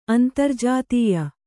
♪ antarjātīya